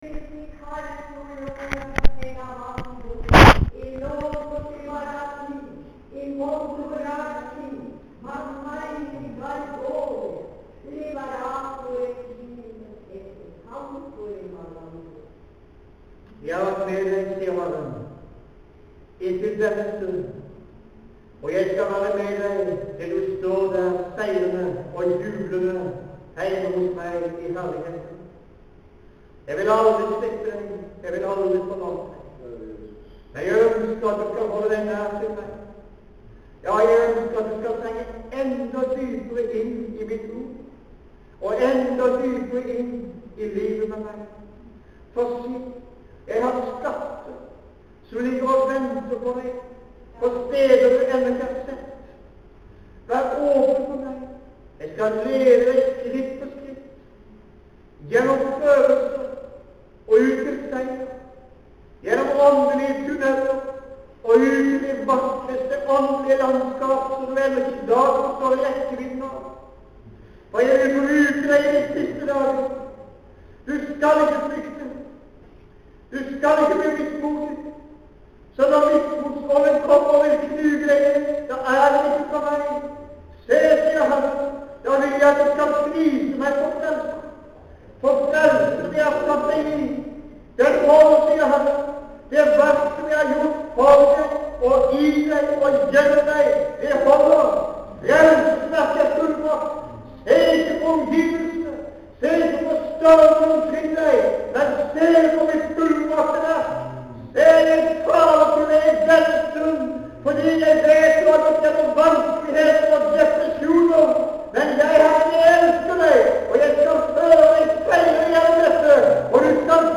I slutten av møtet kom der bodskap gjennom tyding av tungetale der det vart sagt:
Ein mann talte om Filip og Hoffmannen, Filip leida ei vekking, men vart likevel send ut på ein aude landeveg for å møte denne mannen.